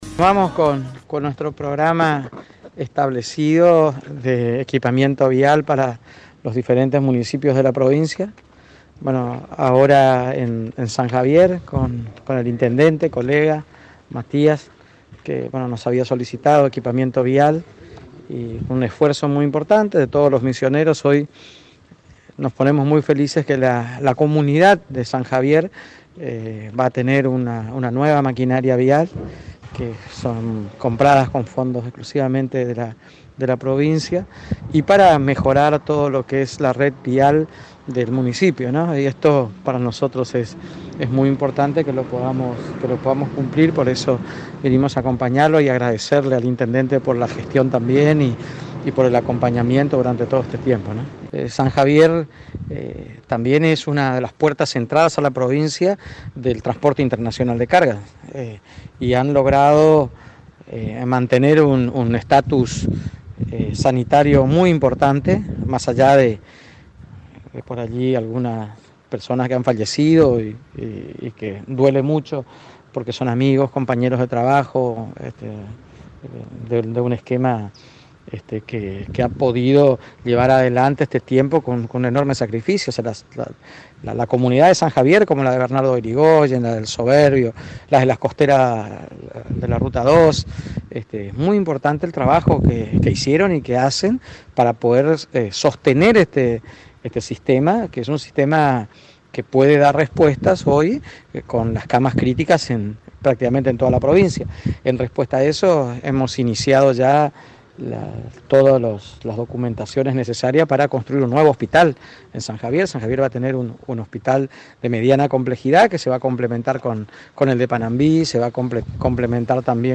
Audio: Oscar Herrera Ahuad, Gobernador de Misiones.